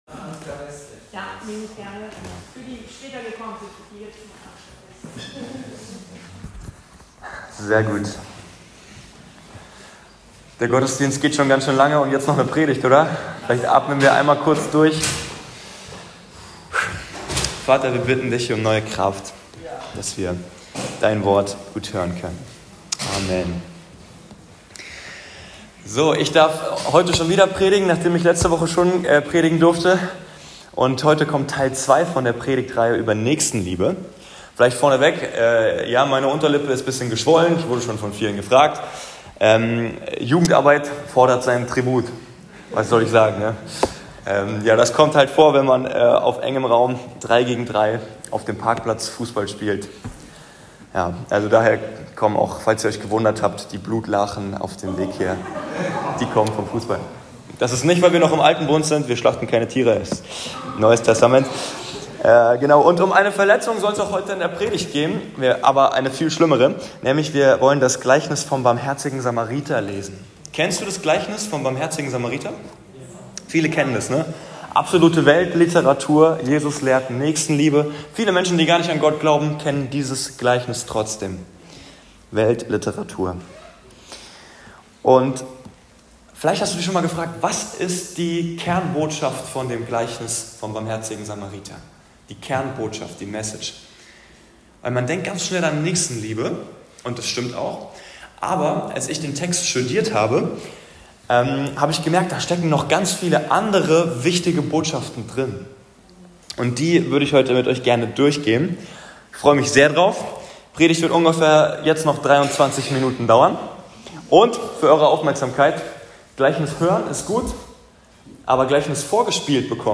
Von Jesus Nächstenliebe lernen: das Gleichnis des barmherzigen Samariters. ~ Anskar-Kirche Hamburg- Predigten Podcast